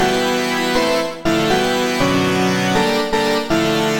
Tag: 120 bpm Electro Loops Synth Loops 689.11 KB wav Key : Unknown